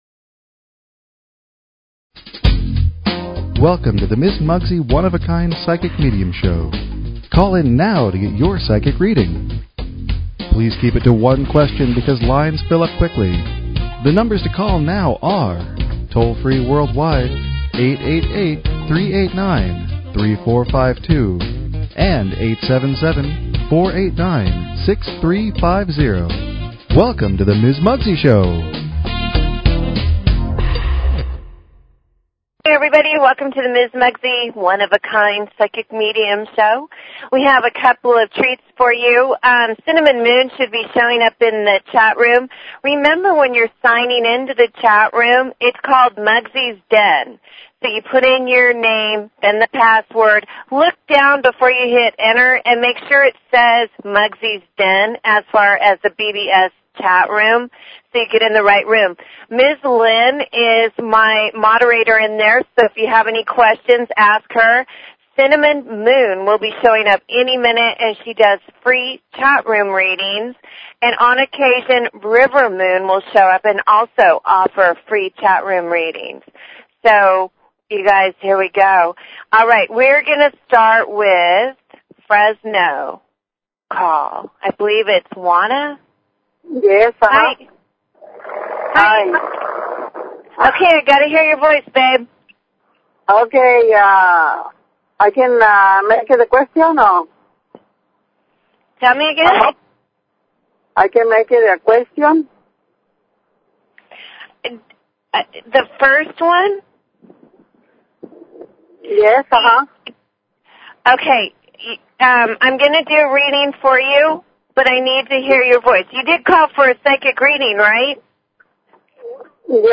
Talk Show Episode, Audio Podcast, One_of_a_Kind_Psychic_Medium and Courtesy of BBS Radio on , show guests , about , categorized as